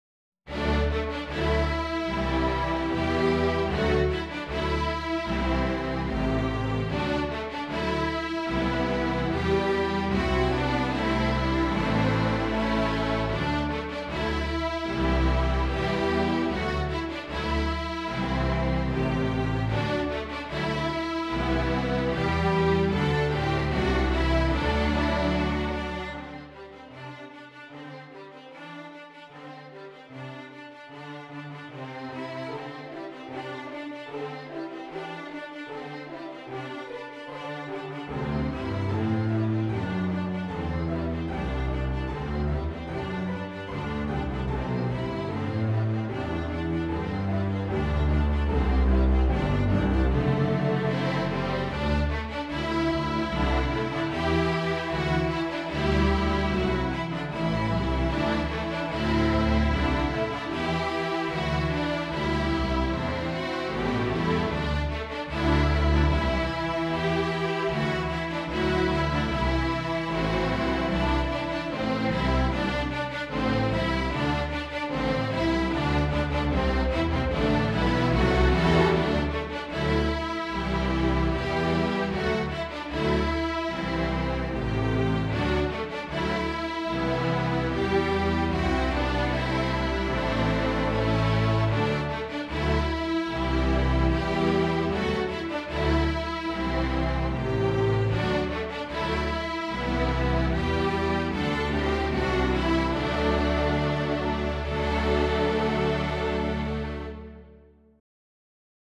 Difficulty: Gr..1.5 or Medium Easy